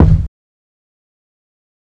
GD Kick.wav